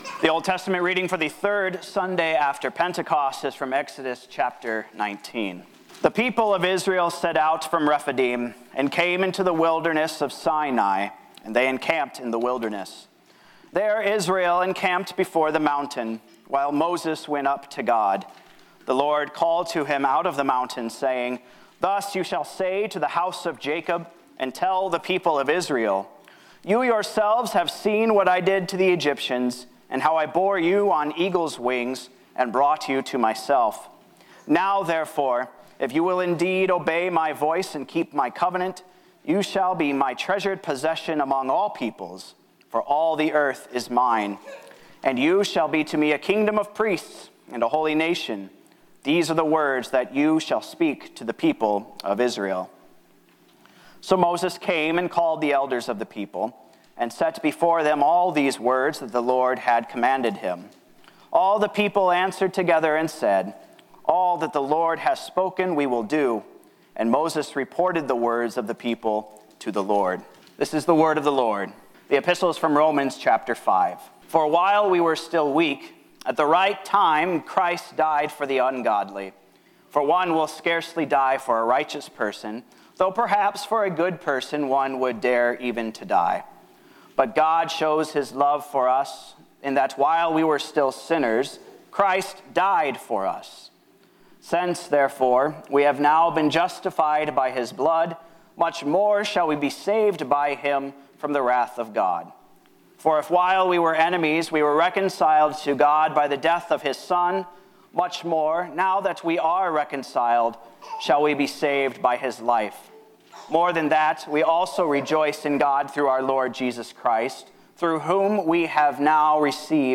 Word & Sermon Weekly – Third Sunday after Pentecost -June 18, 2023